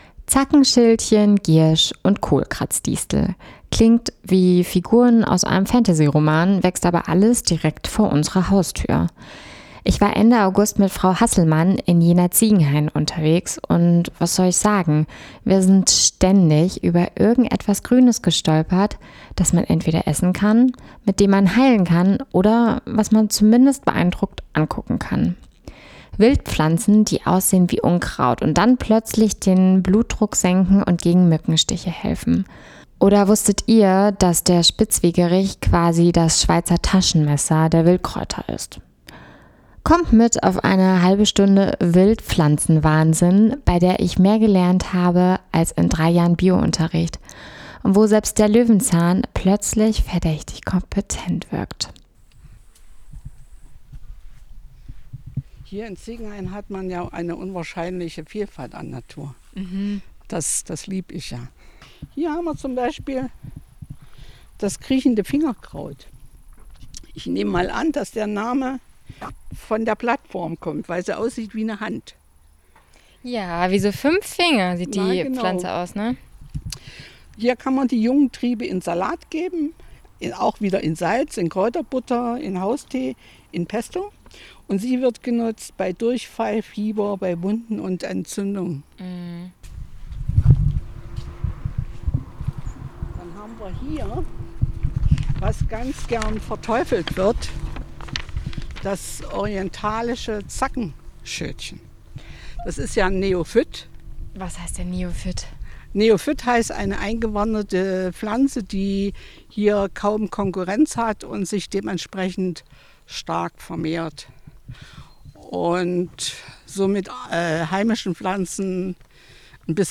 Ein halbst�ndiger Spaziergang voller Aha-Momente, Pflanzenpower und einem neuen Blick auf die Wiese vor der eigenen T�r. Dein Browser kann kein HTML5-Audio.